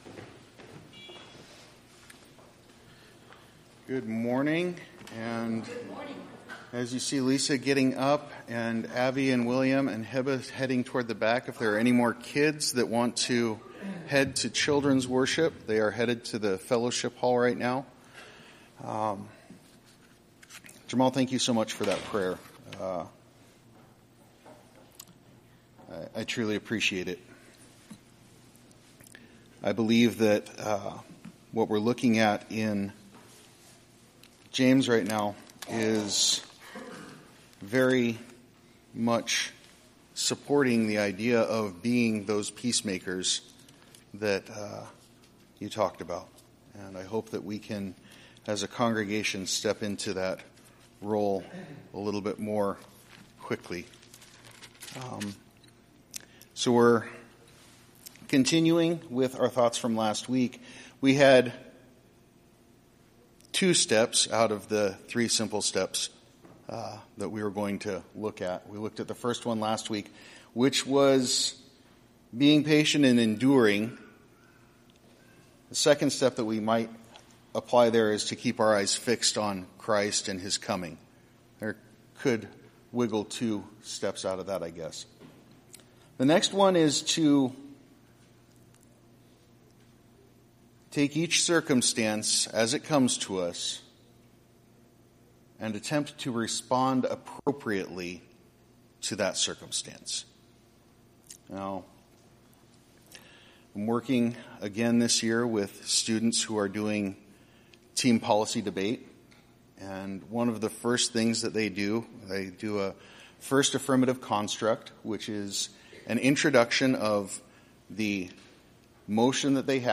Sermon-Audio-October-8-2023.mp3